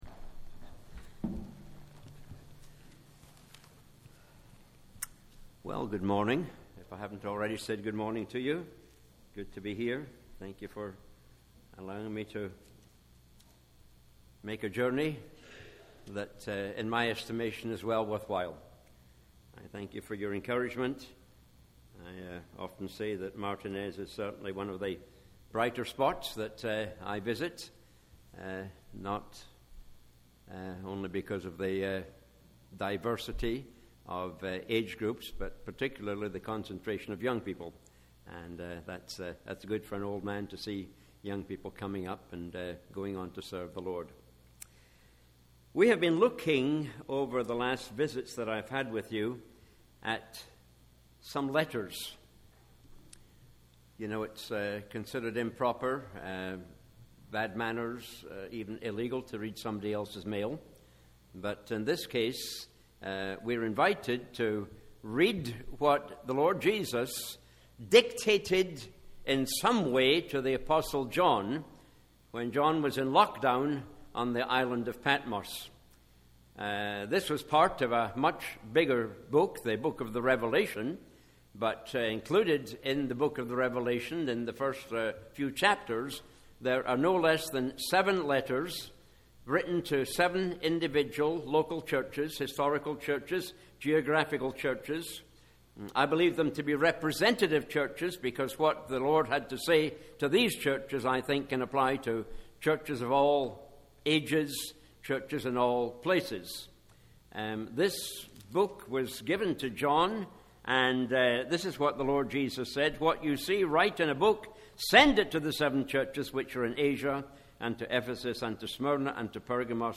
Weekly Sermons - Martinez Bible Chapel - Page 12